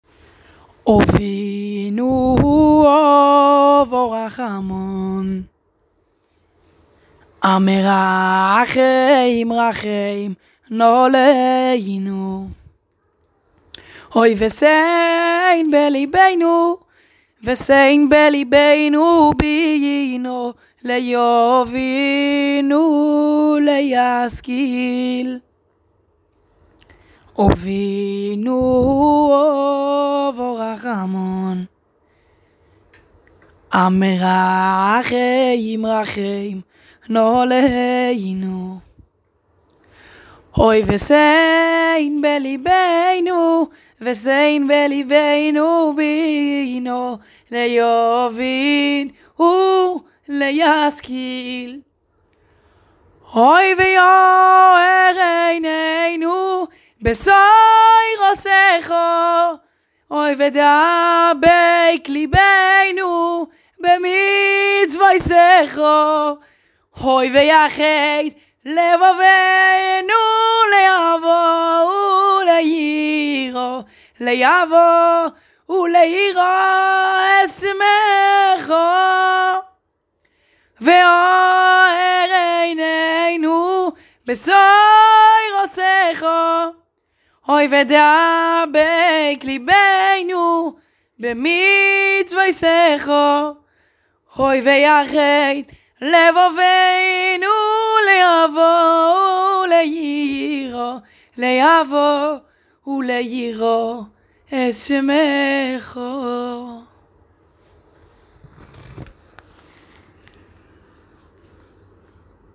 היי חברה אני מעלה כעת סקיצה קצרה מהלחן שלי, הייתי שמח לתגובות הערות והארות.